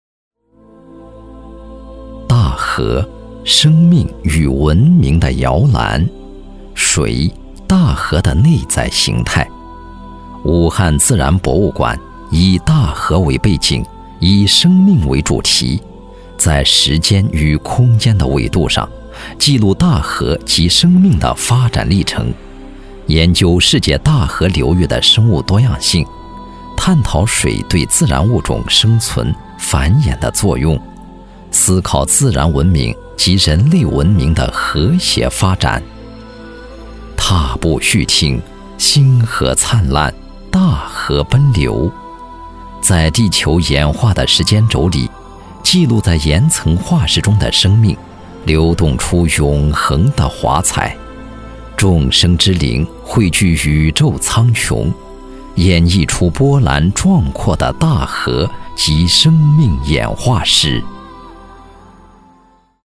自然科教纪录片配音